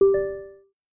new_message.mp3